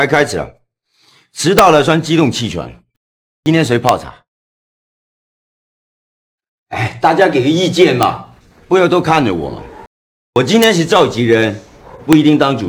Fängslande AI-röst för fotbollskommentering
Play-by-Play
Hög energi